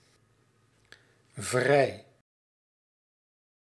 Ääntäminen
Synonyymit los vormeloos vrijgezel vrijgesteld onbezet onbelemmerd Ääntäminen Tuntematon aksentti: IPA: /vrɛi/ IPA: /frɛi/ Haettu sana löytyi näillä lähdekielillä: hollanti Käännös Konteksti Ääninäyte Adjektiivit 1.